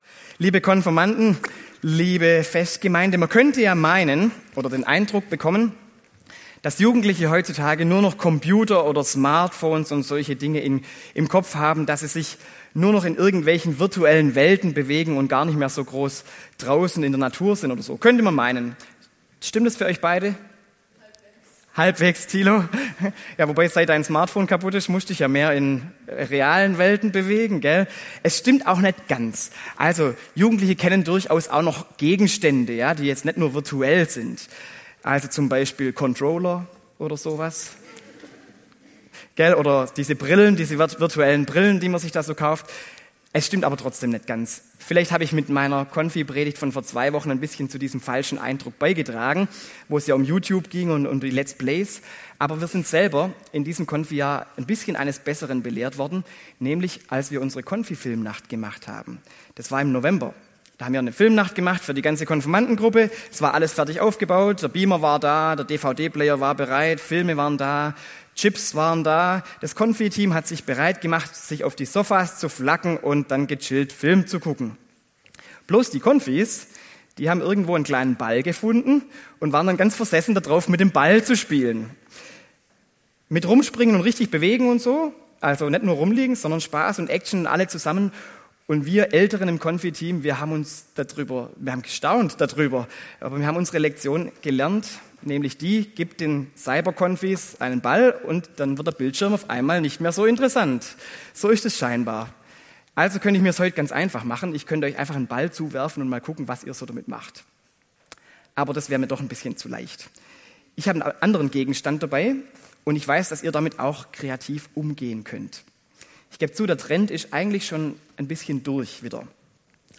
Predigt zur Konfirmation in Bernloch